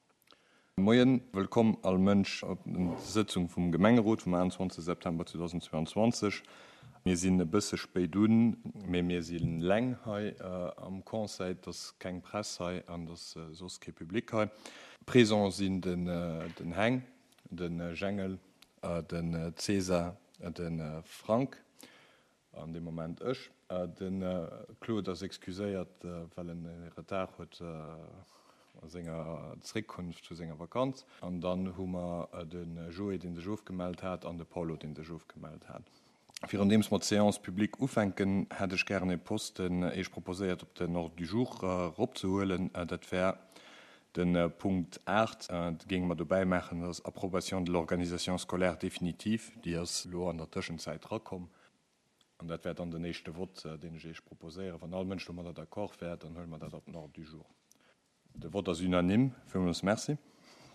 Conseil Communal  du mercredi 28 septembre 2022 à 19h00 heures Centre Culturel Larei en la salle Bessling